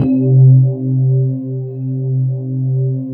44 SYNTH 1-L.wav